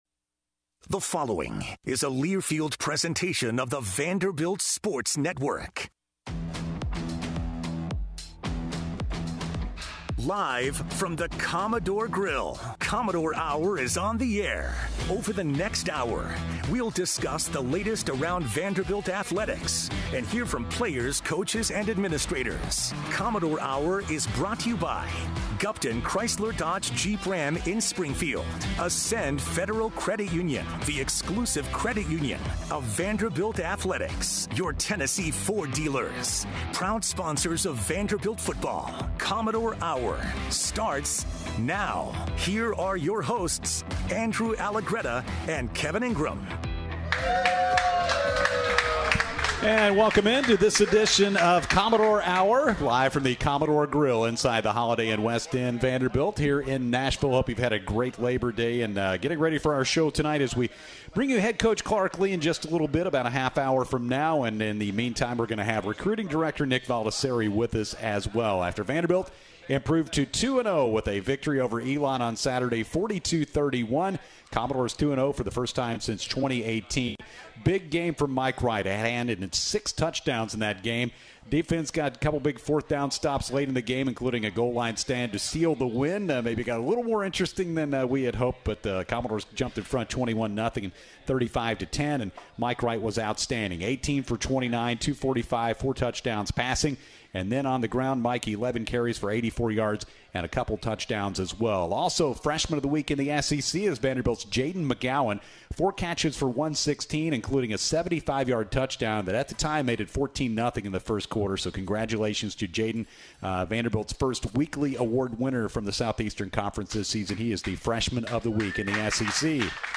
Guests on this week's Commodore Hour after Vandy's 42-31 win over Elon: